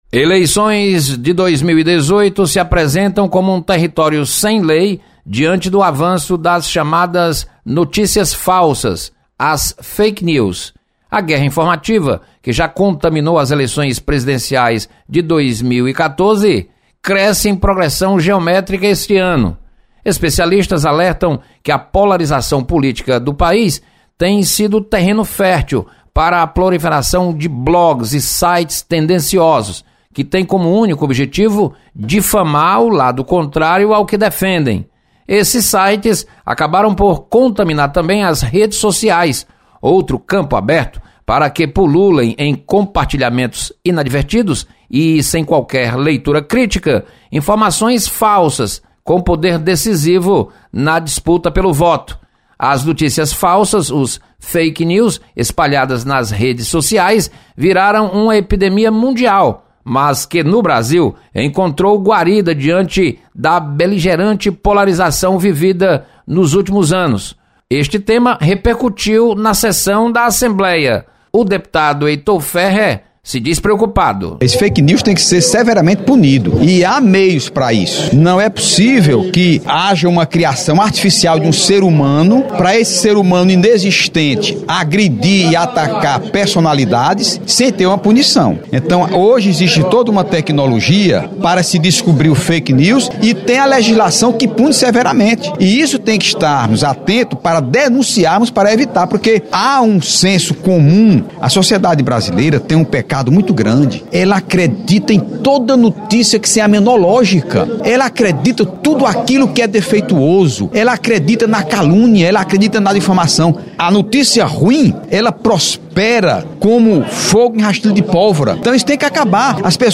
Deputados mostram preocupação com avanço das notícias falsas. Repórter